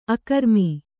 ♪ akarmi